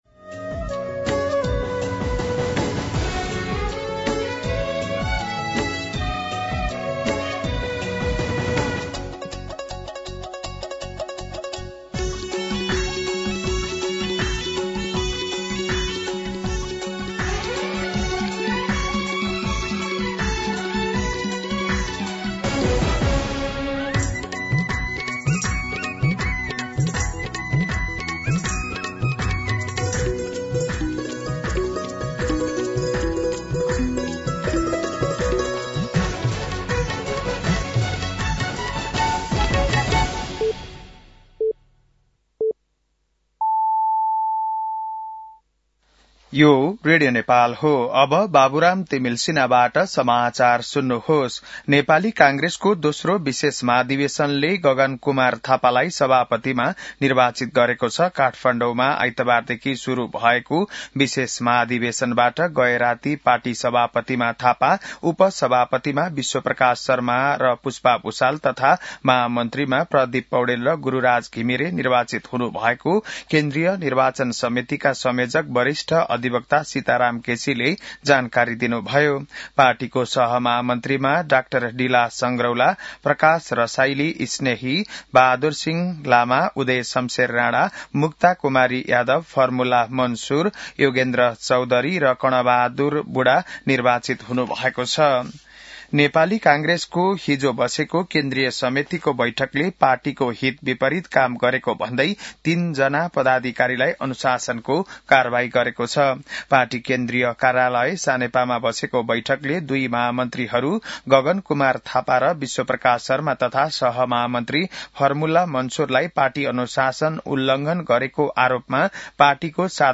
बिहान ११ बजेको नेपाली समाचार : १ माघ , २०८२
11-am-Nepali-News-5.mp3